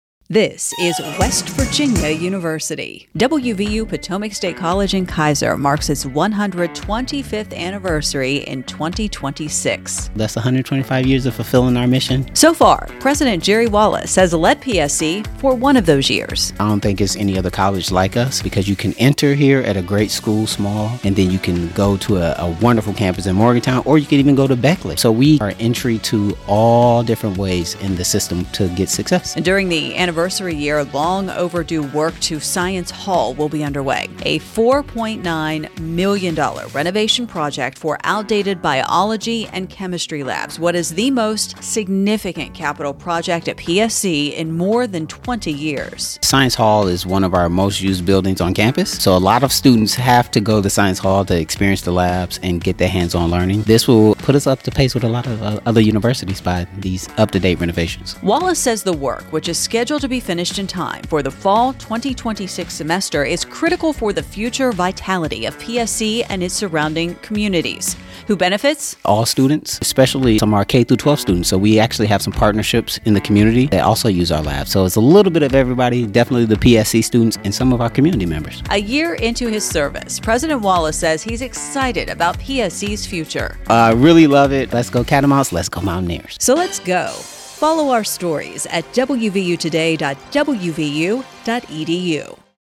PSC radio spot
psc-radio-spot.mp3